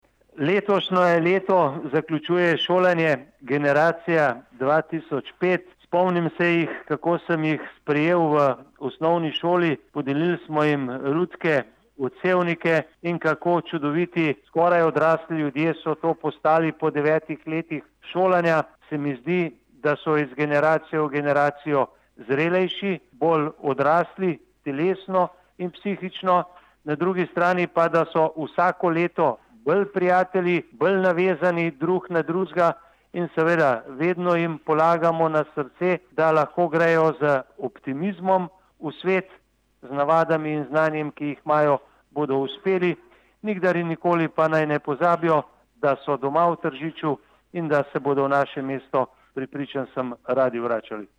izjava_mag.borutsajoviczupanobcinetrzic_odlicnjaki.mp3 (1,2MB)